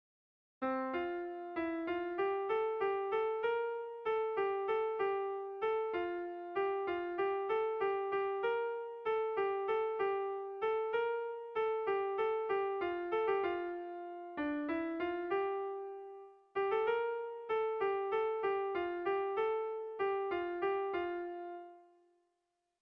Erlijiozkoa
Zortziko txikia (hg) / Lau puntuko txikia (ip)
A1A2A3A4